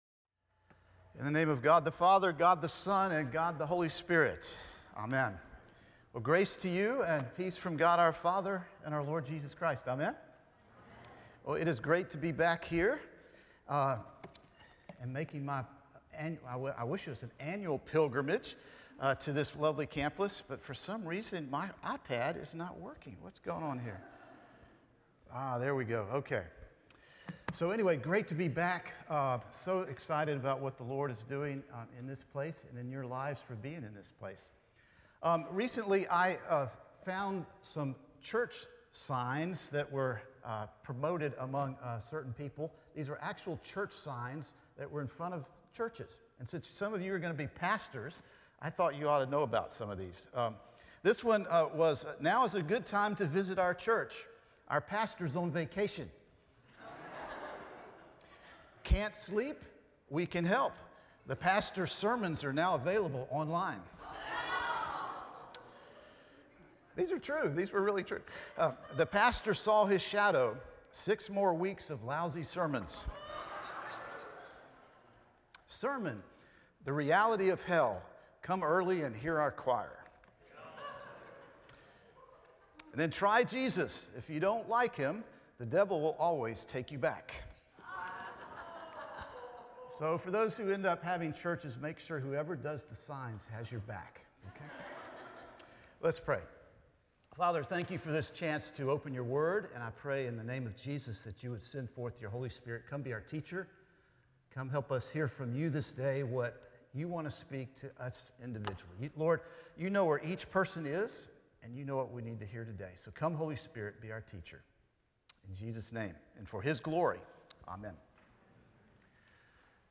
Chapel at Estes with Archbishop Foley Beach
Chapel at Estes with Archbishop Foley Beach Sermon Title: Are You Prepared to Pray?